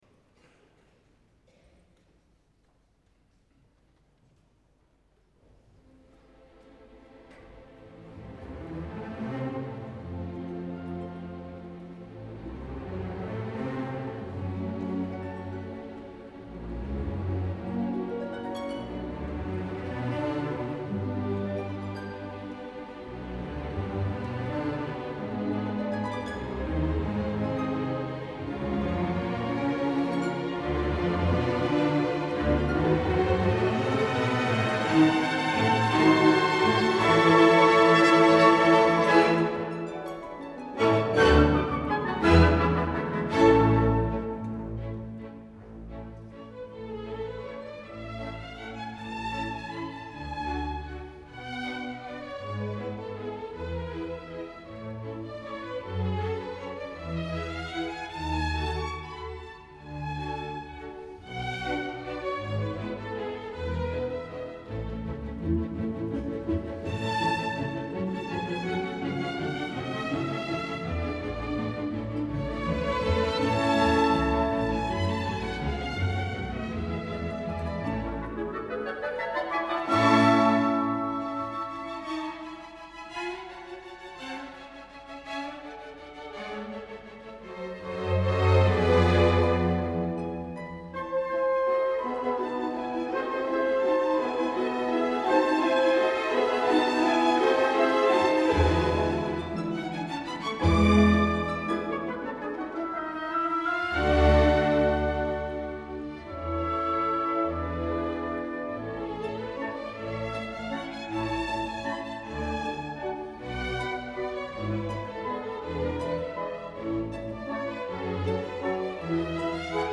Gustavo Dudamel
Dos dies abans, el dia 25 d’octubre actuaven a Großen Musikvereinssaal a Viena amb l’altre programa de la gira.
Göteborgs Symfoniker